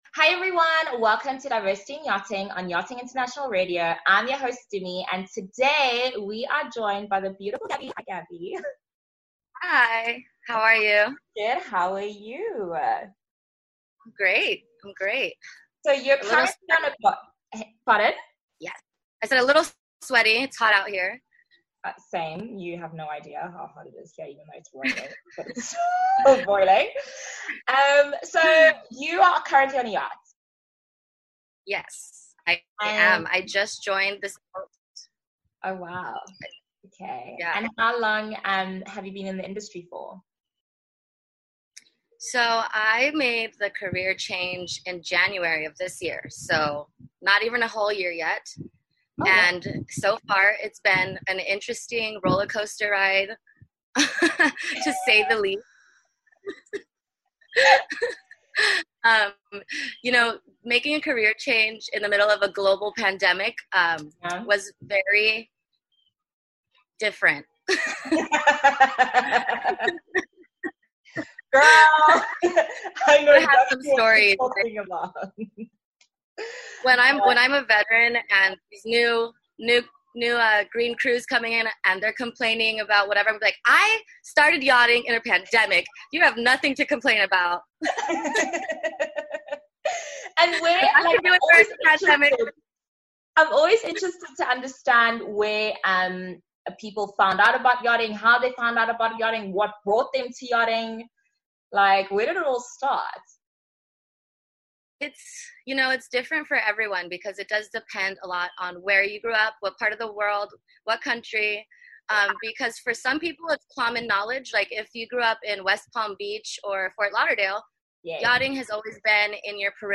Tune in for a VERY interesting and informative interview.